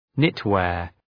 Προφορά
{‘nıt,weər}